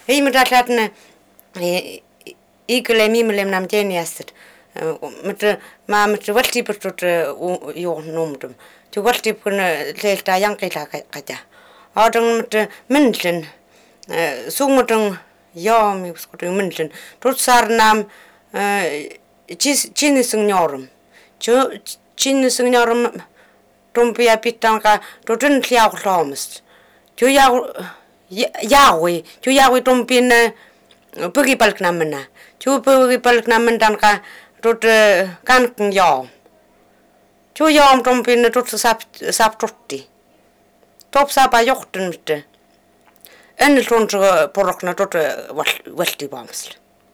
InformantYear of Birth (Informant)Subdialect (Informant)Collector Date of RecordingPlace of Recordingrecorded by